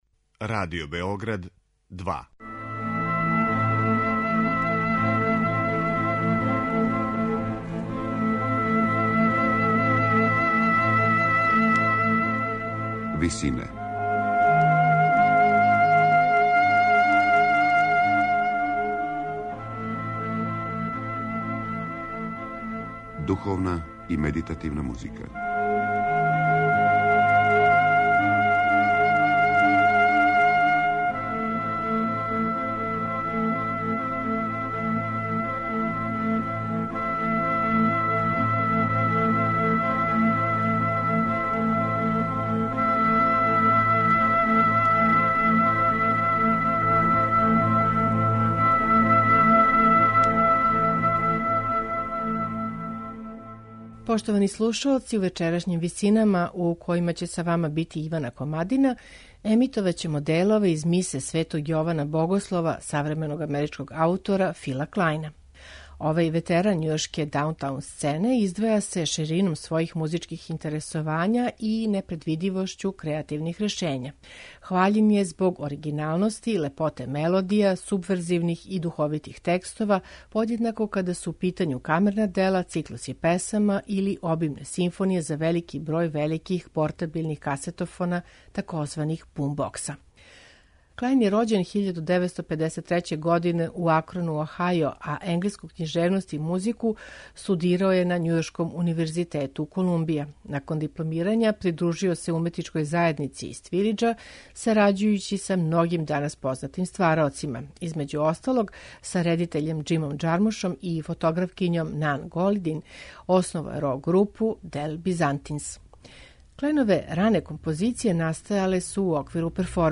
за оргуљама.